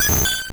Cri de Mélodelfe dans Pokémon Or et Argent.